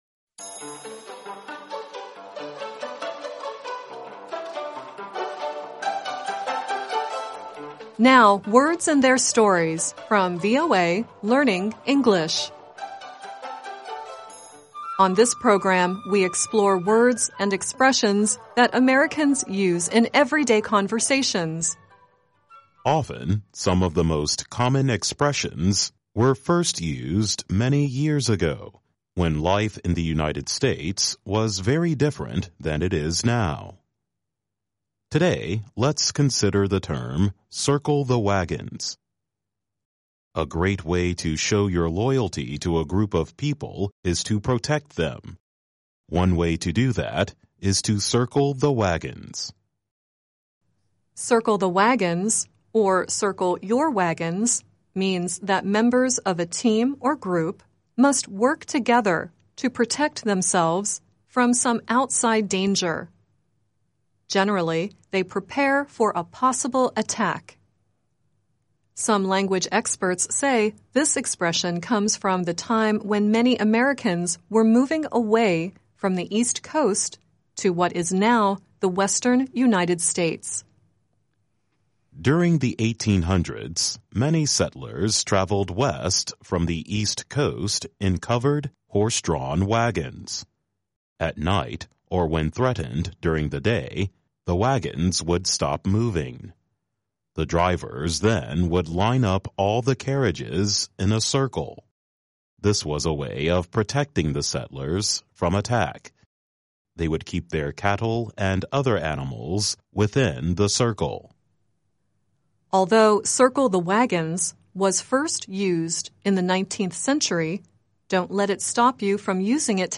Song at the end is “Come The Reckoning.”